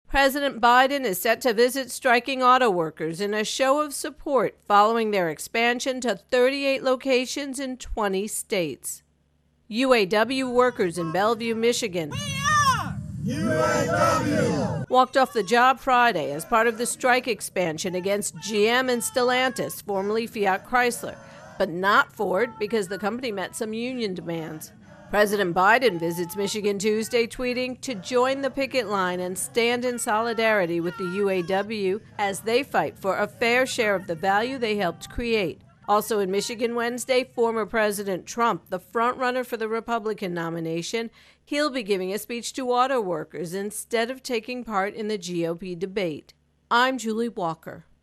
reports on Auto Workers Strike